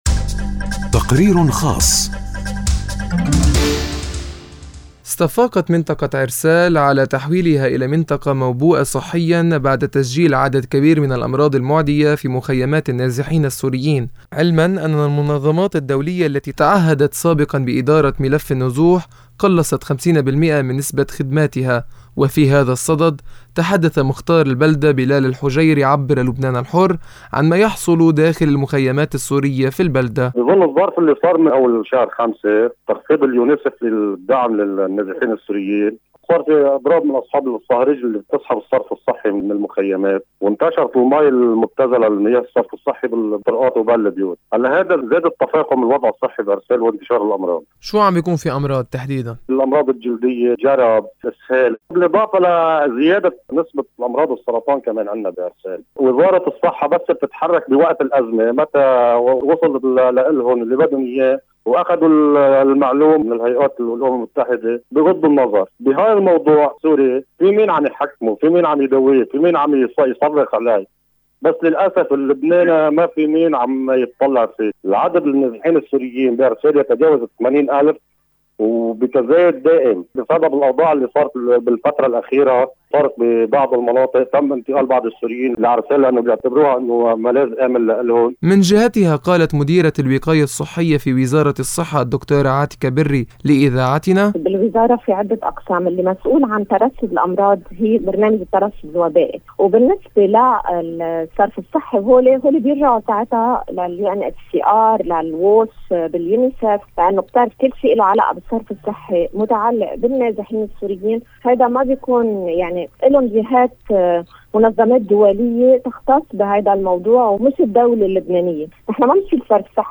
التقرير